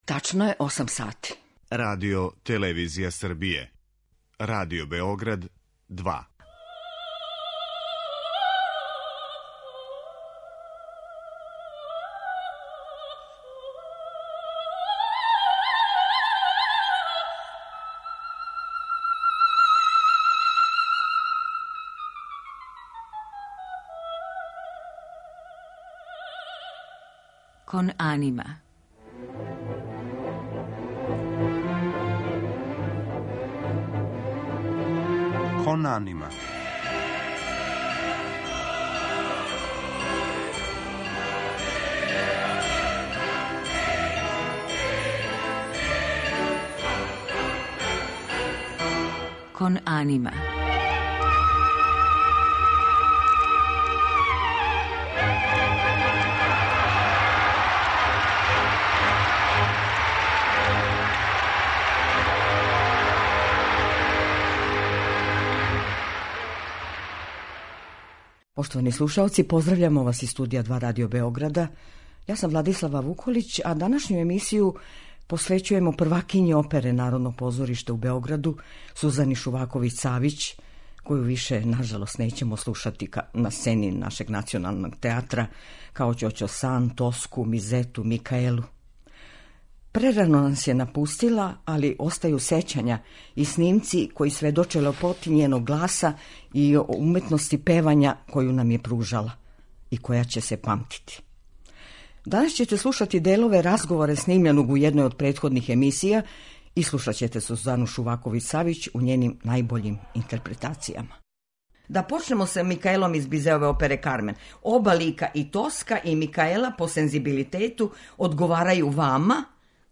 У музичком делу биће емитоване арије